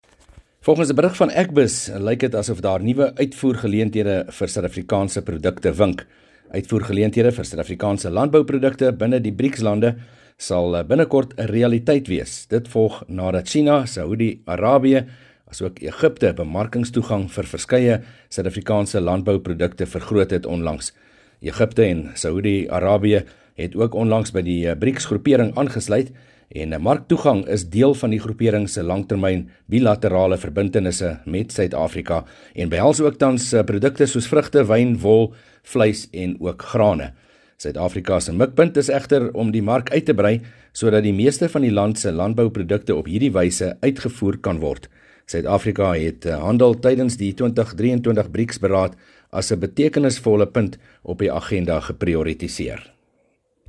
berig oor nuwe uitvoergeleenthede wat vir Suid-Afrikaners in die vooruitsig gestel word.